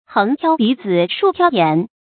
橫挑鼻子豎挑眼 注音： ㄏㄥˊ ㄊㄧㄠ ㄅㄧˊ ㄗㄧ ㄕㄨˋ ㄊㄧㄠ ㄧㄢˇ 讀音讀法： 意思解釋： 比喻百般挑剔。